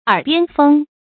注音：ㄦˇ ㄅㄧㄢ ㄈㄥ
讀音讀法：
耳邊風的讀法